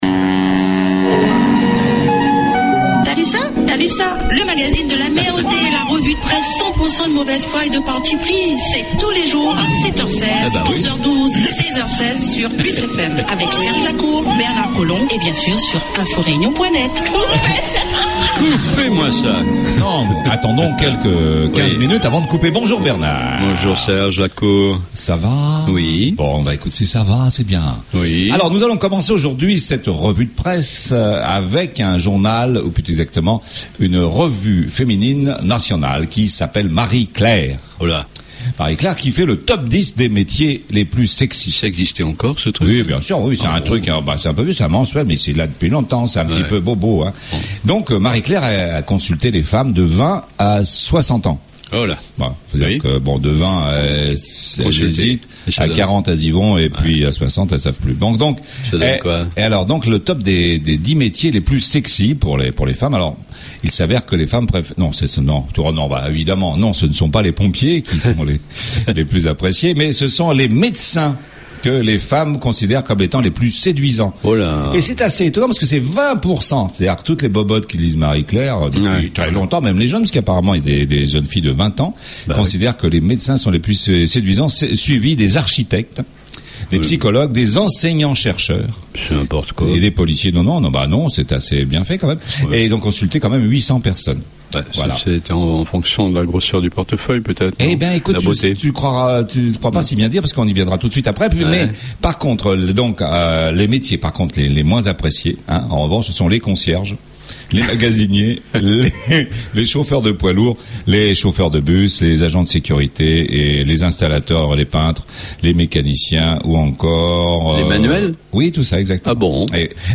La Revue de presse du jour , THALUSSA, le magazine de l'amer.